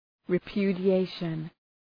Shkrimi fonetik{rı,pju:dı’eıʃən}